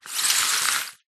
Звук скольжения журнала по столу немного дольше чем предыдущий